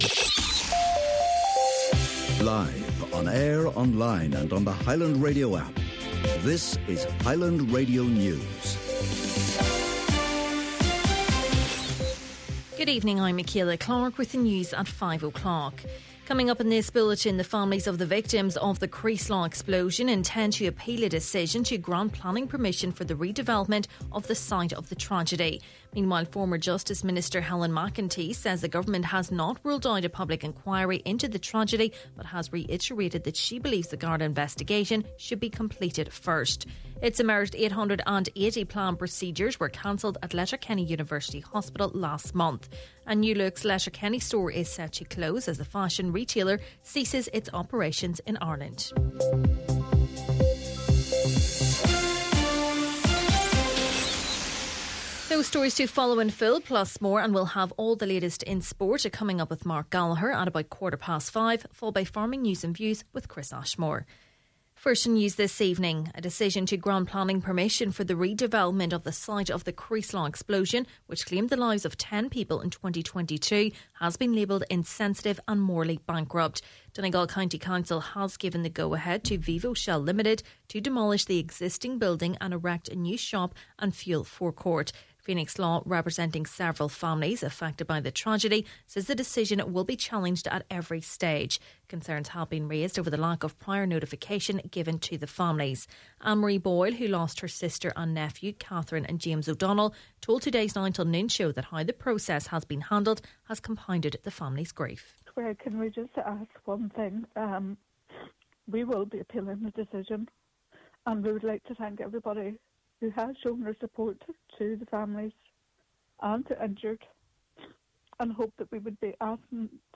Main Evening News, Sport, Farming News and Obituaries – Thursday, February 20th